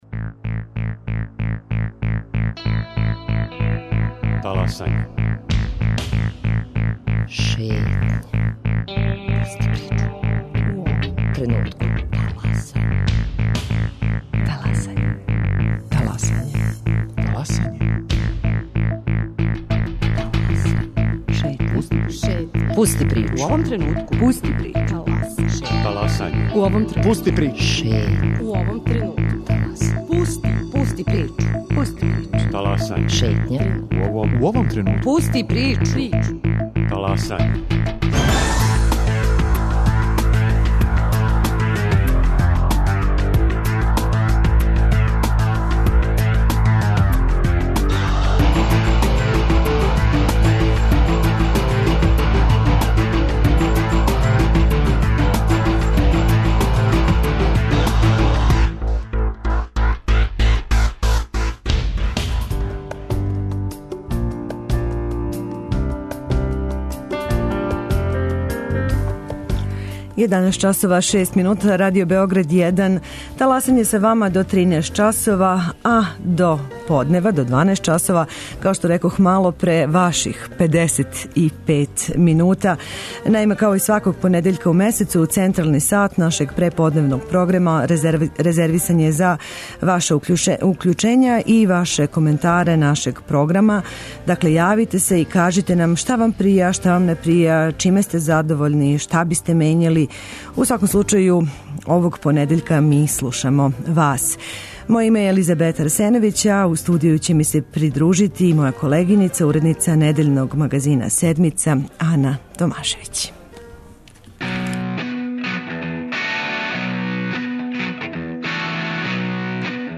Као и сваког првог понедељка у месецу, централни сат преподневног програма резервисан је за укључења слушалаца Радио Београда 1 и њихове коментаре нашег програма.